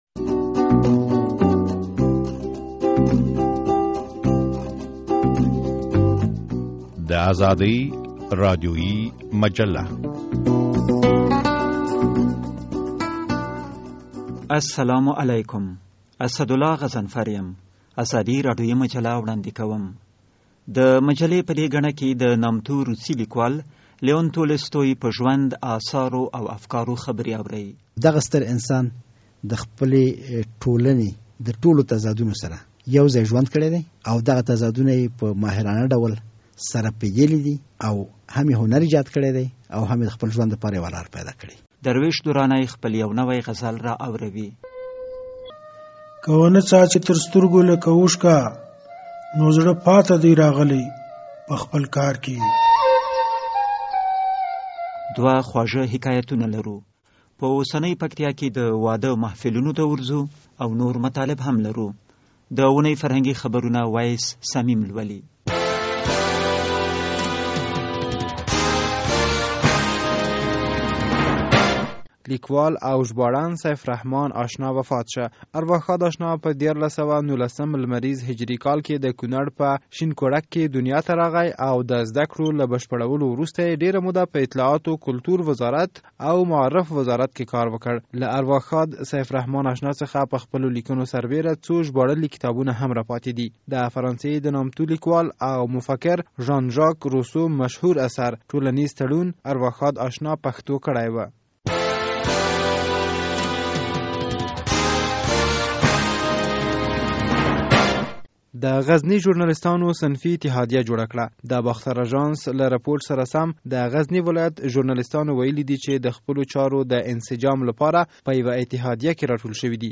ازادي راډیويي مجله